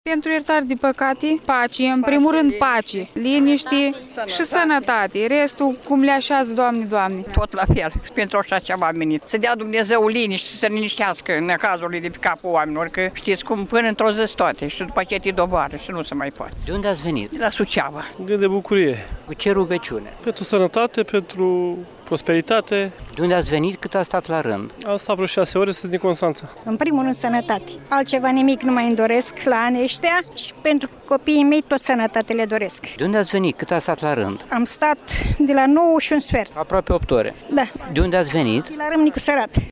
a stat de vorbă cu câţiva pelerini
15-oct-ora-7-vox-pop-pelerini.mp3